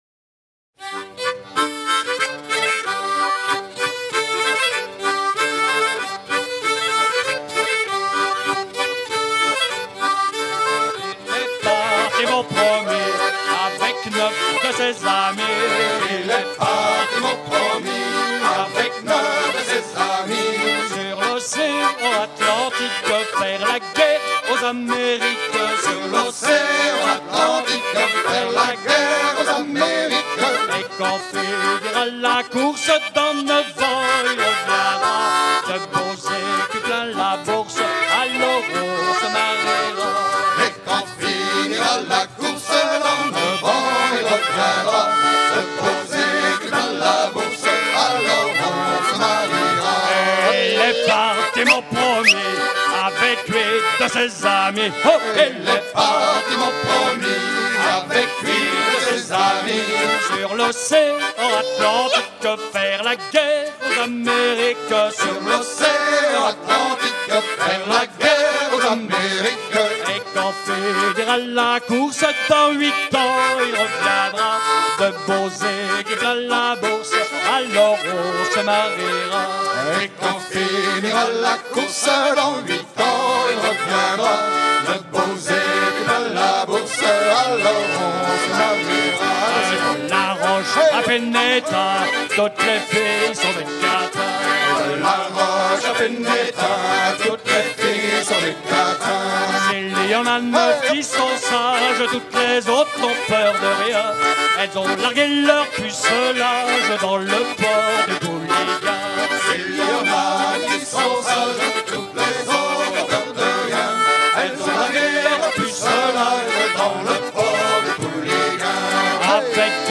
danse : rond
Genre énumérative